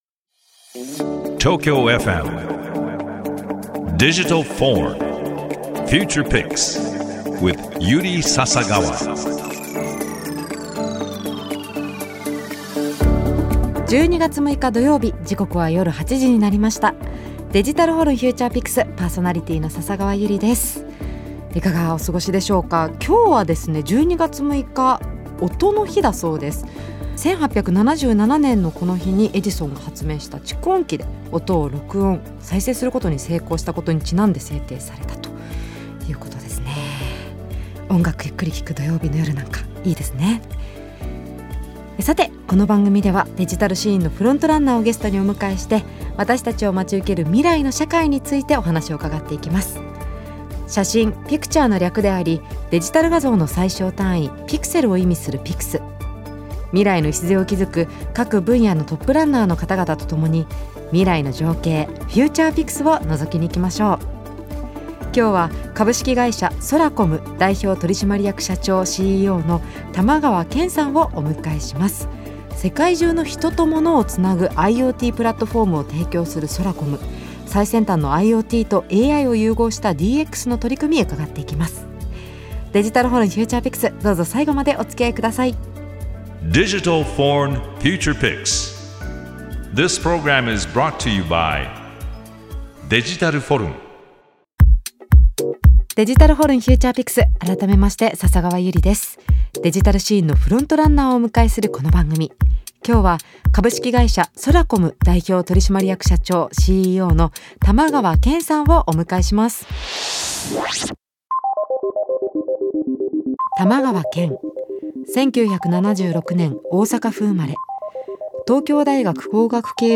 デジタルシーンのフロントランナーをゲストにお迎えして、 私達を待ち受ける未来の社会についてお話を伺っていくDIGITAL VORN Future Pix。